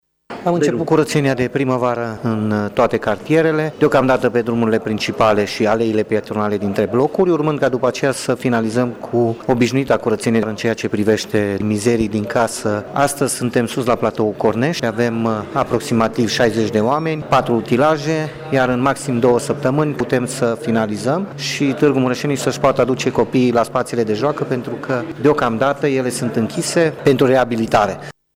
Viceprimarul Claudiu Maior susține că în circa două săptămâni se va încheia atât curățenia la Platou, cât și reabilitarea locurilor de joacă pentru copii: